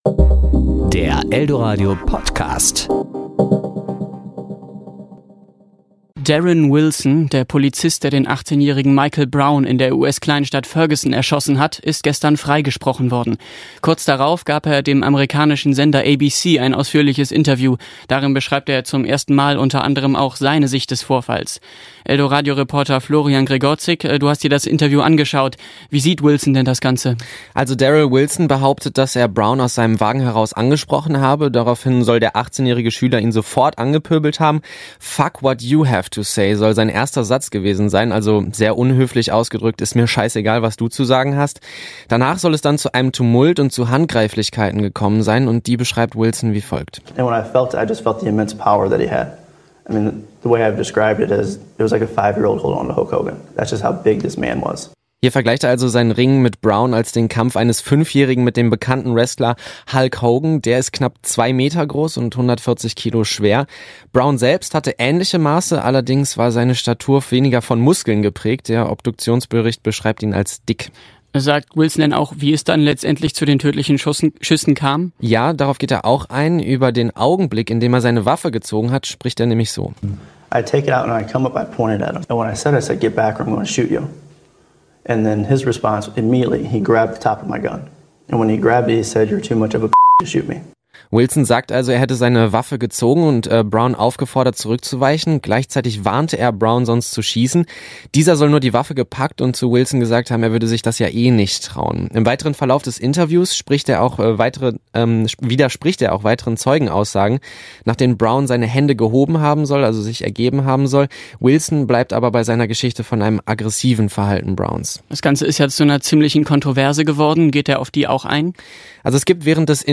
Kollegengespräch  Ressort